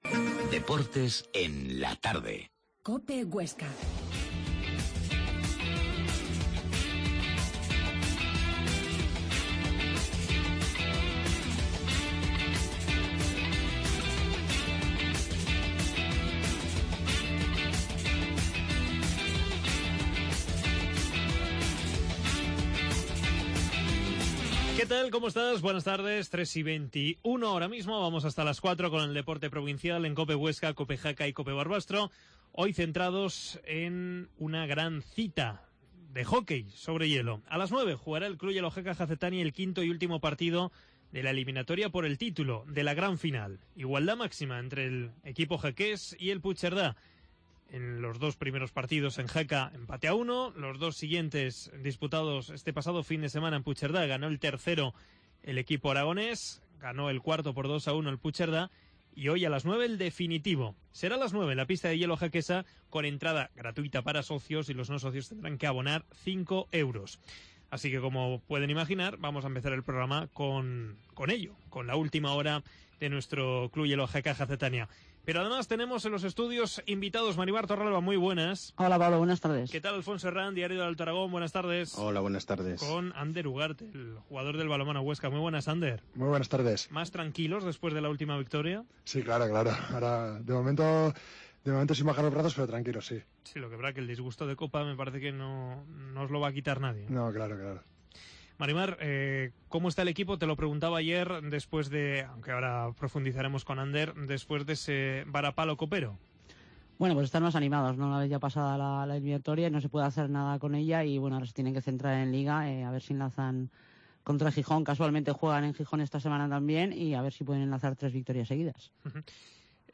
Previa de la final del CH Jaca, entrevista